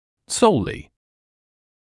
[‘səullɪ][‘соулли]только, исключительно